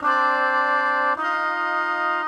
GS_MuteHorn_105-DF.wav